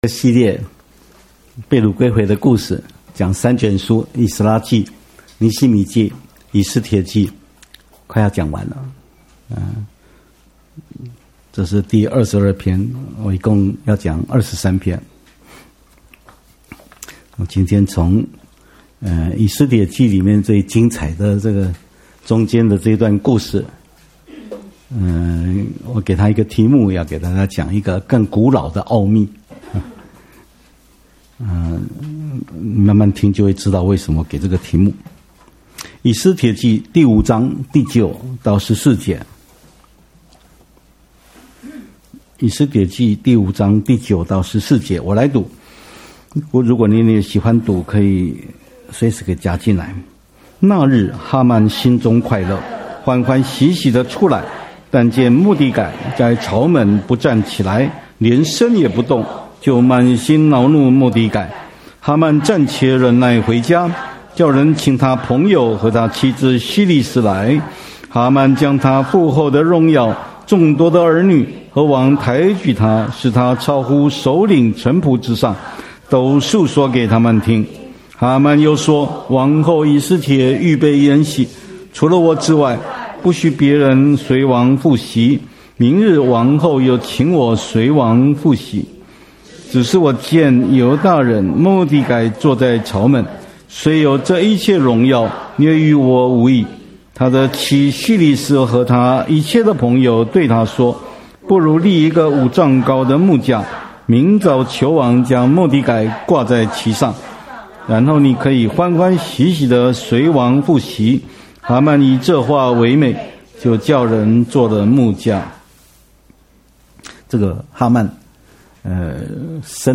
-敬拜
-講道